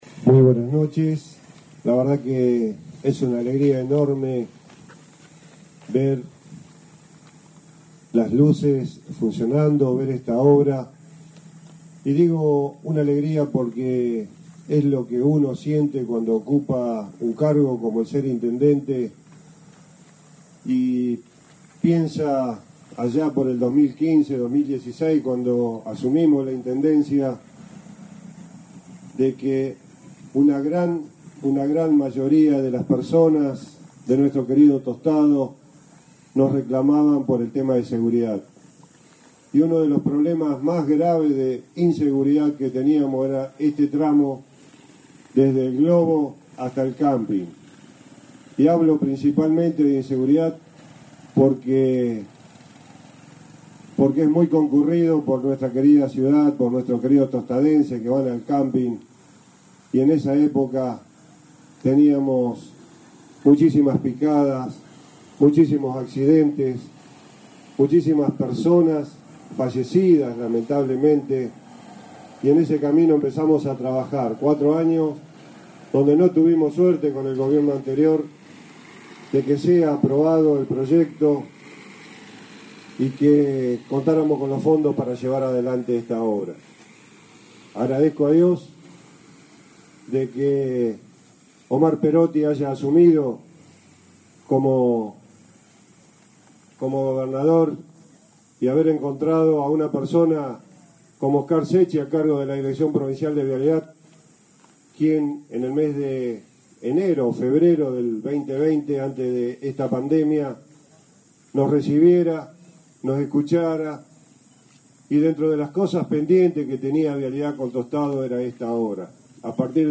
Este martes a la noche en Tostado quedó inaugurada la obra de iluminación del ingreso sur a la ciudad por ruta provincial 2, entre el Camping Municipal y el Globo de entrada.
Durante el acto, el intendente Enrique Mualem mostró satisfacción por la concreción de la obra, y dio cuenta del trabajo conjunto que se realizó con la provincia para poder lograr la anhelada iluminación del sitio: